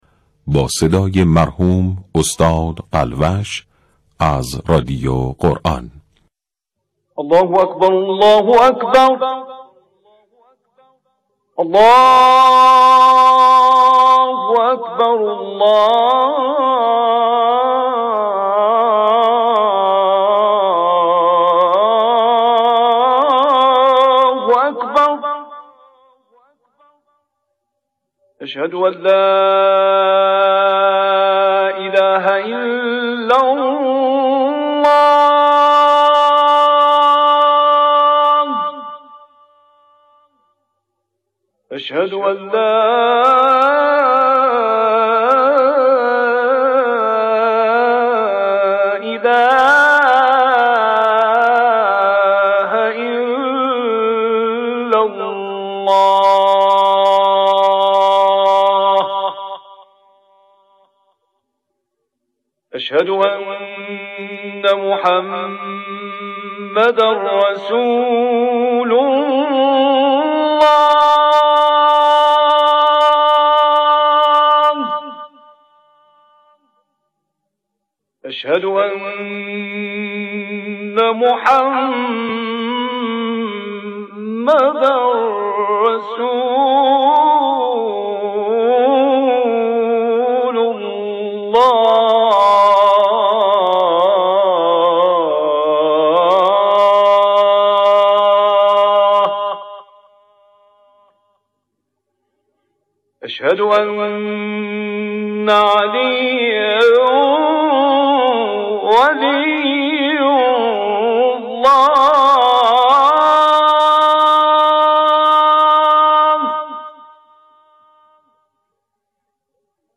اذان امروز به وقت تهران با صدای استاد مرحوم راغب مصطفی غلوش (نغمه و مقام رَست/ماهور) از شبکه رادیویی قرآن پخش شد.